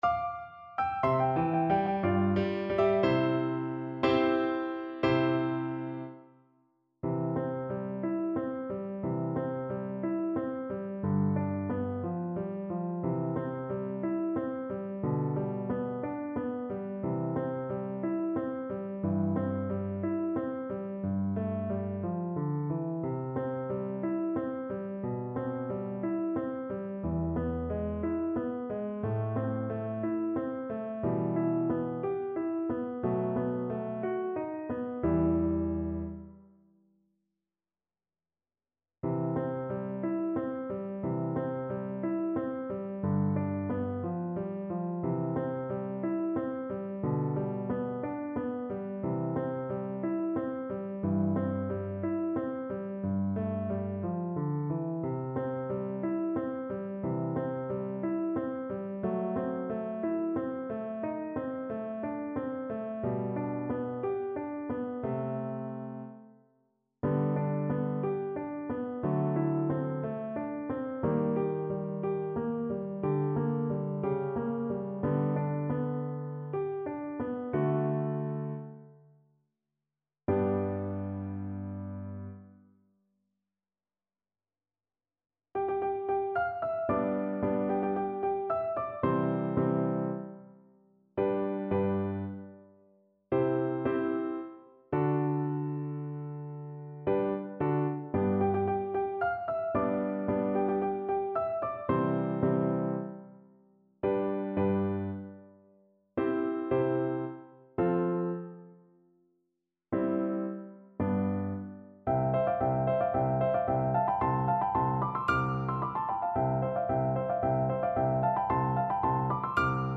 Play (or use space bar on your keyboard) Pause Music Playalong - Piano Accompaniment Playalong Band Accompaniment not yet available reset tempo print settings full screen
C major (Sounding Pitch) (View more C major Music for Tenor Voice )
2/4 (View more 2/4 Music)
Andante =c.60
Classical (View more Classical Tenor Voice Music)